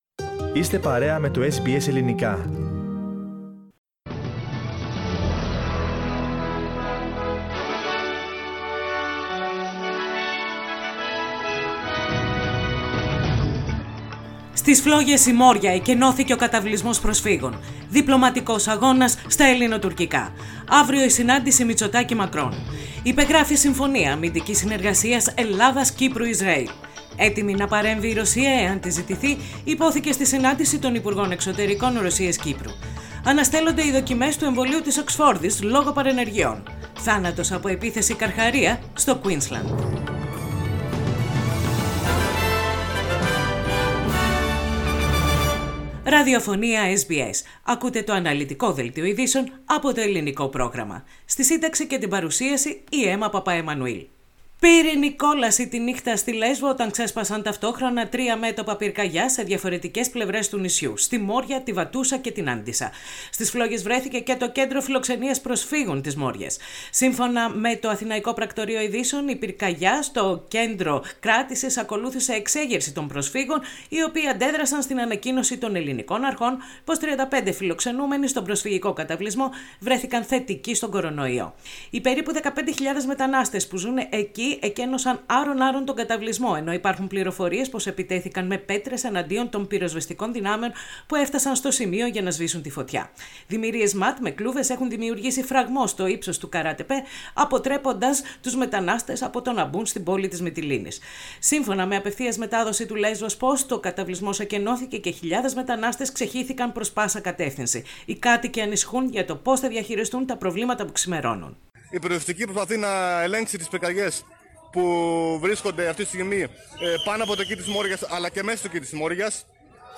Δελτίο Ειδήσεων - Τετάρτη 9.9.20
Νύχτα κόλασης στην Λέσβο. Στις φλόγες ο προσφυγικός καταυλισμός στην Μόρια, που εκκενώθηκε απο μετανάστες. Ακούστε τις κυριότερες ειδήσεις της ημέρας απο το Ελληνικό πρόγραμμα της ραδιοφωνίας SBS.